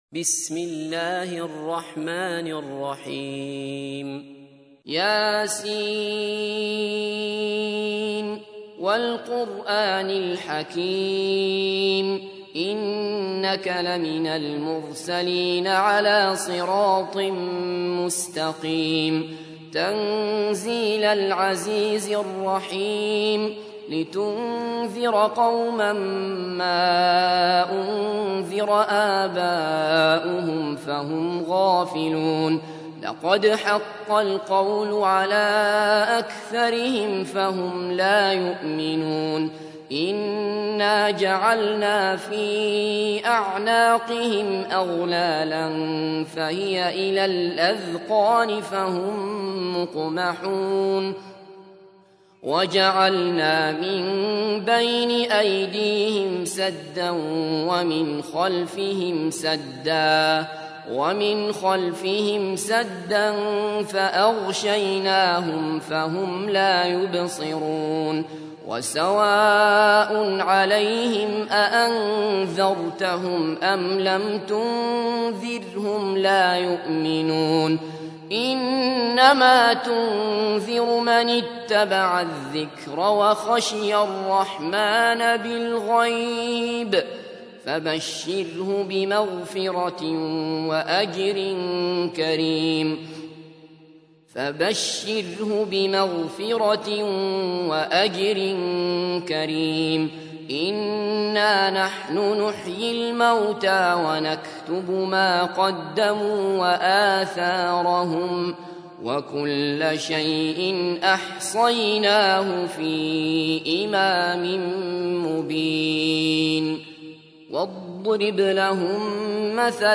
تحميل : 36. سورة يس / القارئ عبد الله بصفر / القرآن الكريم / موقع يا حسين